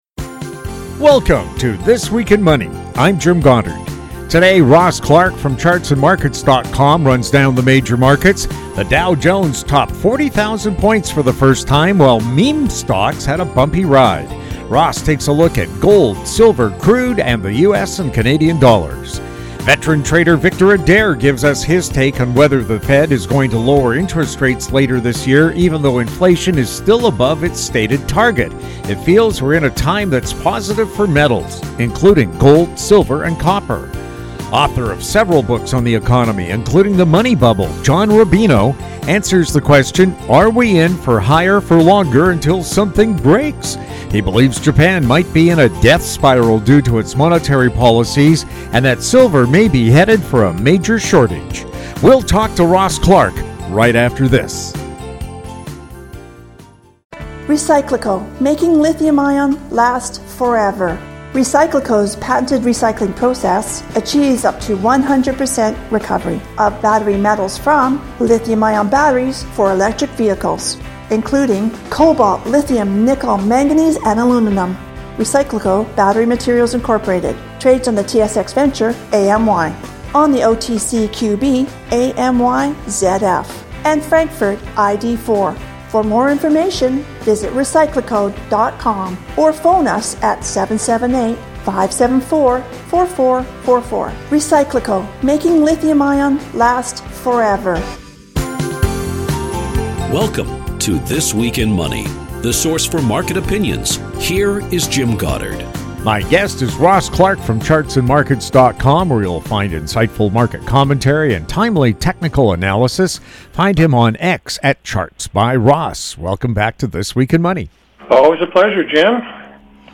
May 18, 2024 | This Week in Money This Week in Money Visit Show Archives This Week in Money presents leading financial news and market commentary from interesting, informative and profound guests. They are some of the financial world's most colorful and controversial thinkers, discussing the markets, economies and more!
New shows air Saturdays on Internet Radio.